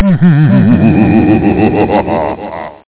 CACKLE.mp3